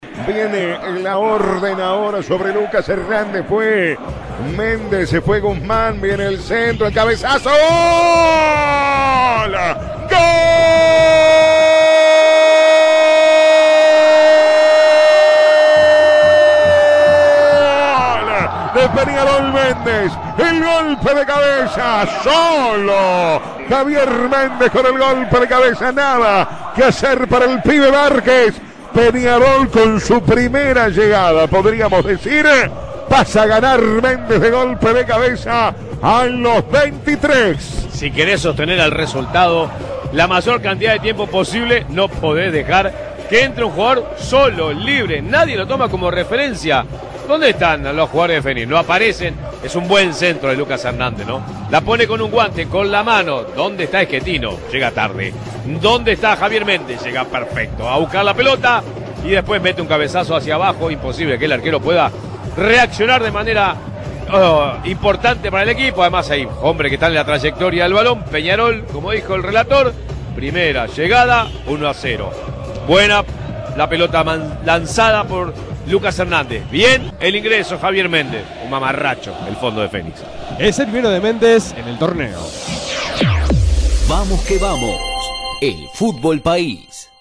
La victoria aurinegra en la voz del equipo de Vamos que Vamos